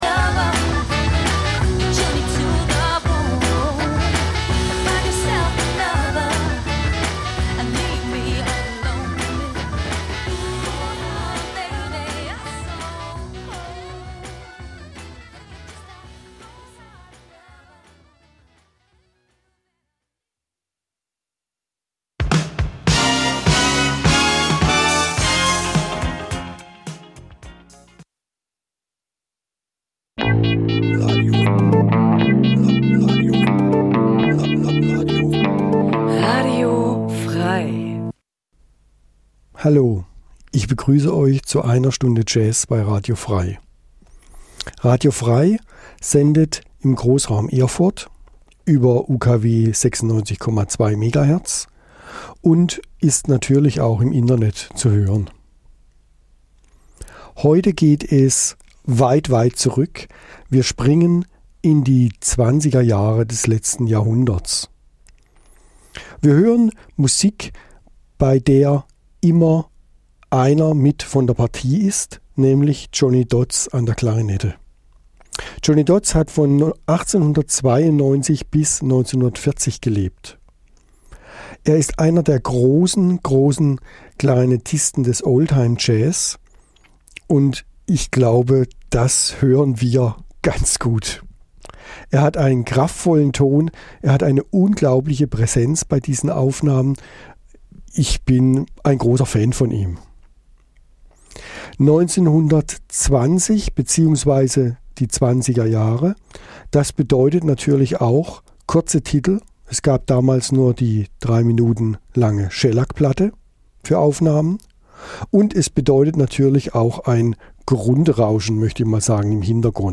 Eine Stunde Jazz